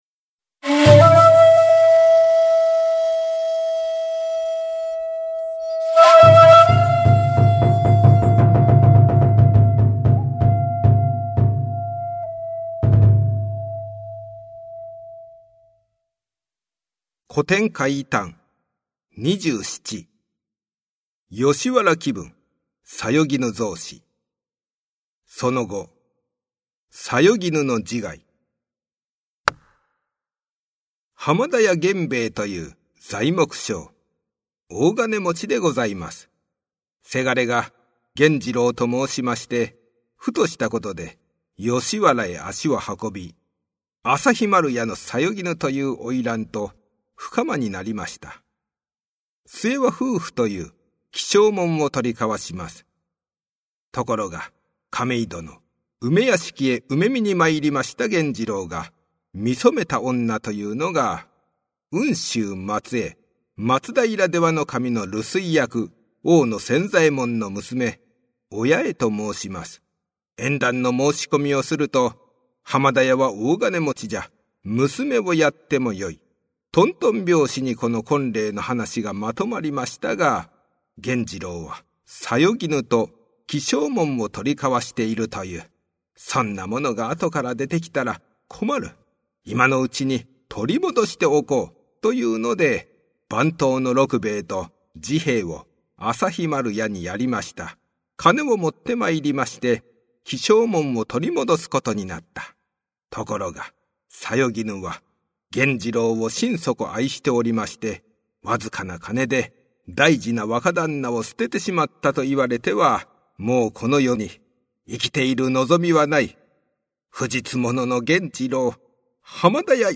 上方講談 古典怪談の世界
名調子で語る「日本の怪談」ここにあり。